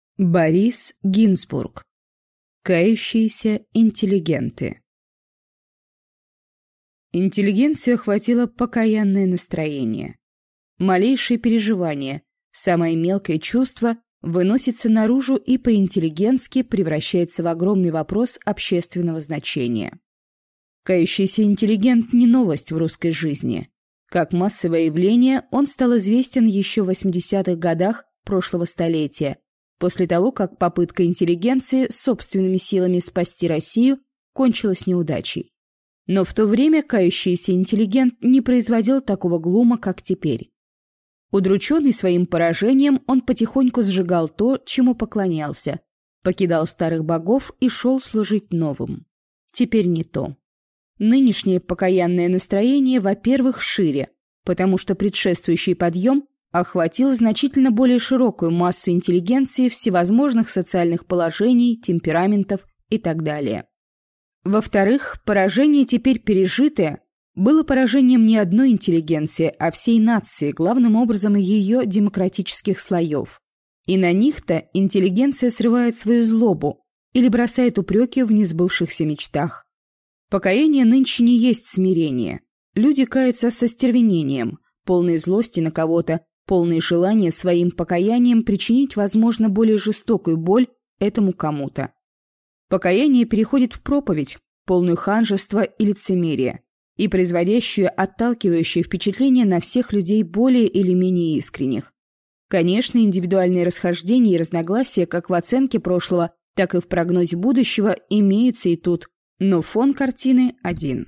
Аудиокнига Кающиеся интеллигенты | Библиотека аудиокниг
Прослушать и бесплатно скачать фрагмент аудиокниги